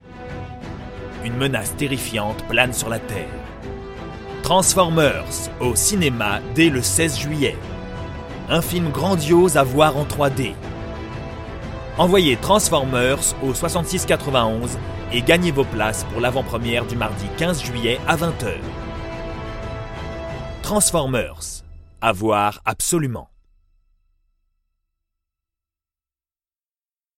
Transformers : voix grave/autorité/événement